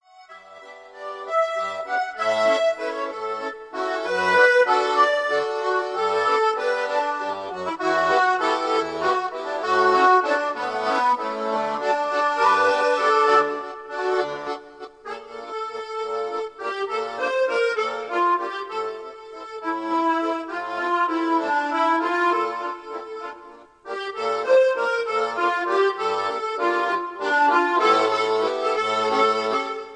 Romantic waltz melody